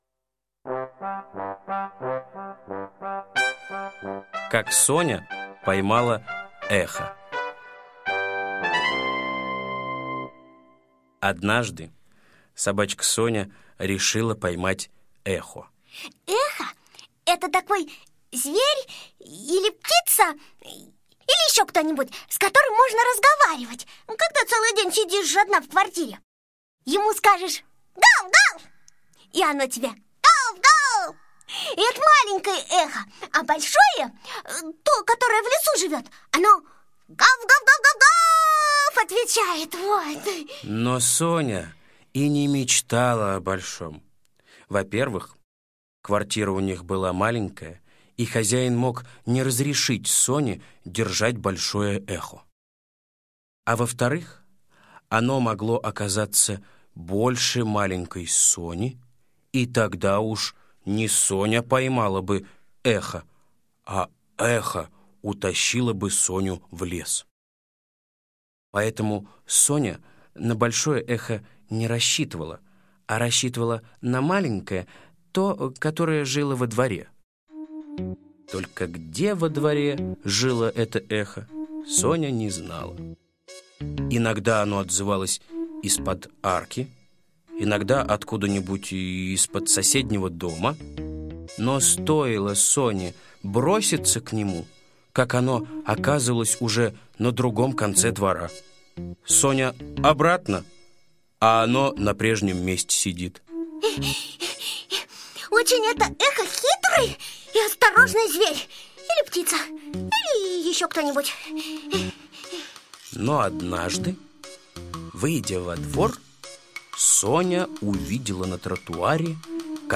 Слушайте Как Соня поймала эхо - аудиосказка Усачева А.А. Сказка про то, как Соня решила поймать эхо, а поймала какое-то животное.